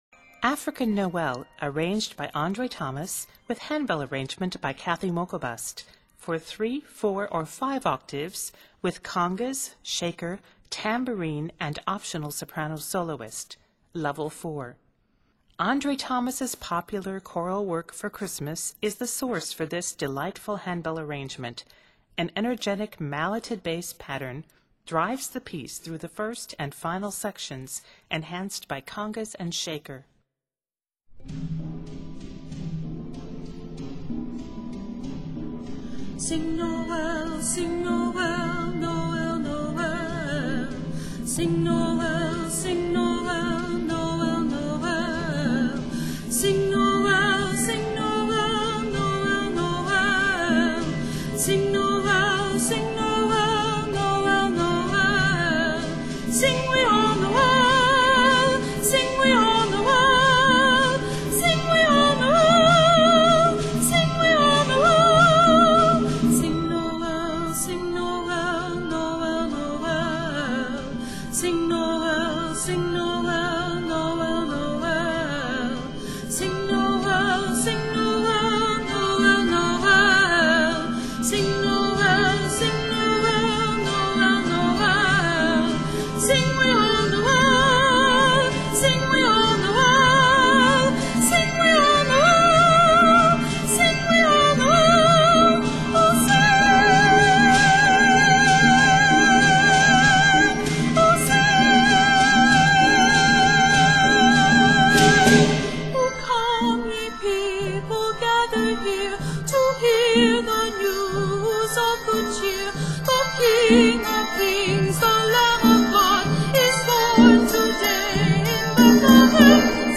This rendition is set in C Major and is 88 measures.